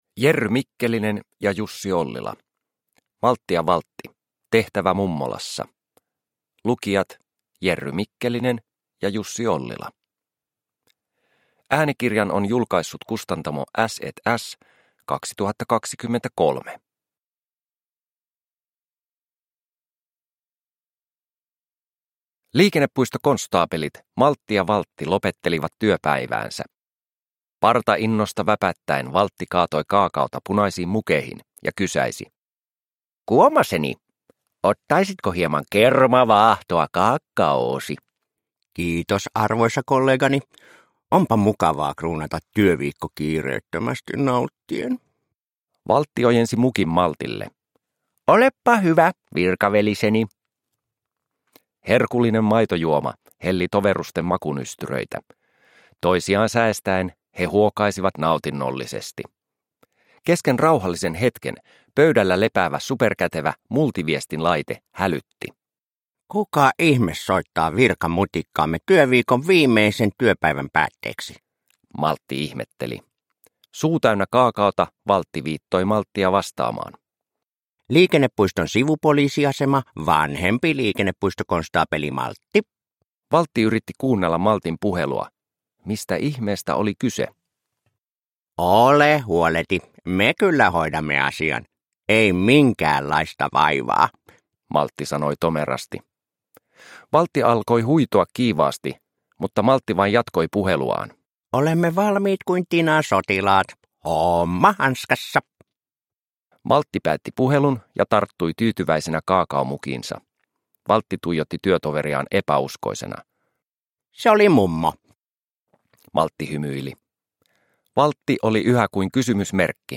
Maltti ja Valtti - Tehtävä mummolassa – Ljudbok – Laddas ner